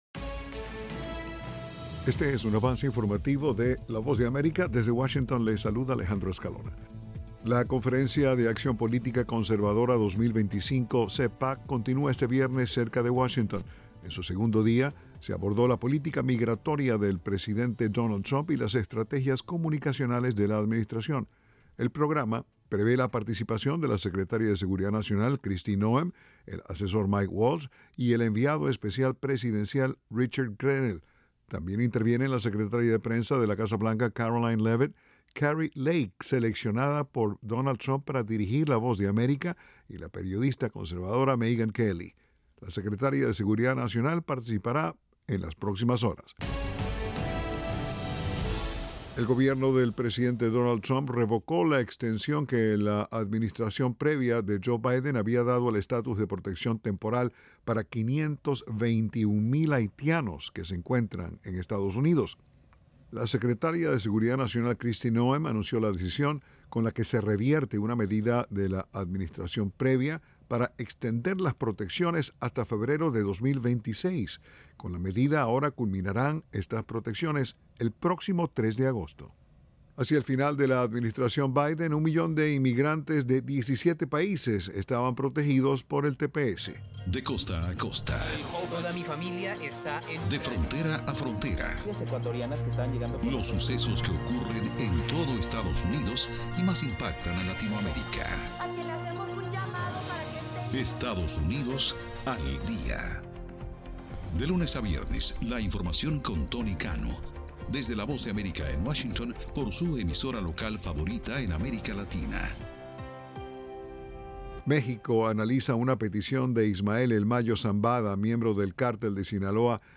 El siguiente es un avance informativo de la Voz de América.